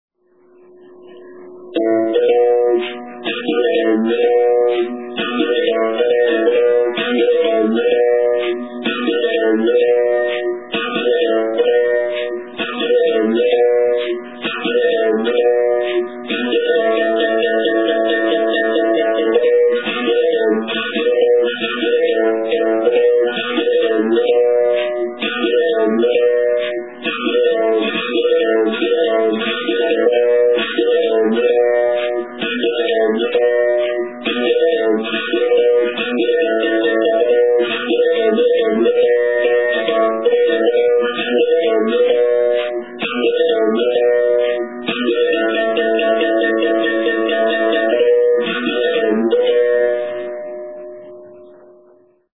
Совершая удары бакетой по струне и периодически прижимая монету к последней, беримбау может издавать три основных звука: открытый (тон), закрытый (тин) и жужжащий звук слегка прижатой к струне монеты (чи).
Ангола